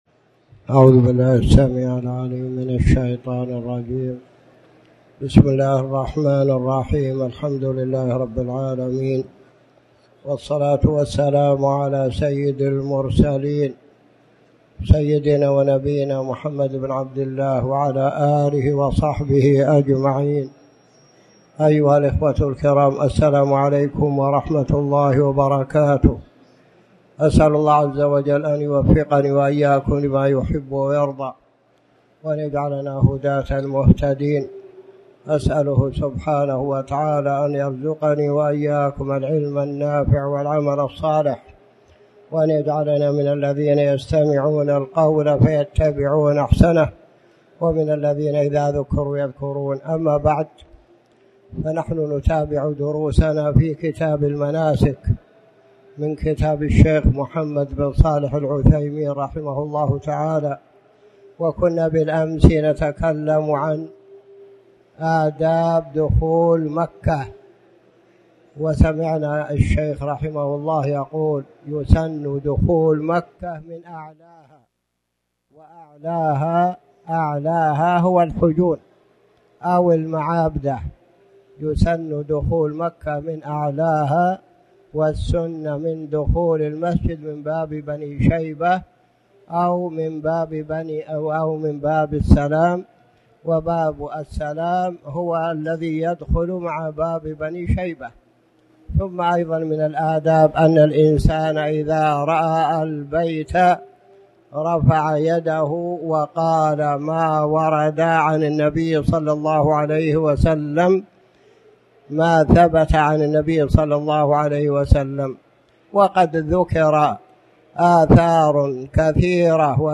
تاريخ النشر ٢٣ ذو الحجة ١٤٣٨ هـ المكان: المسجد الحرام الشيخ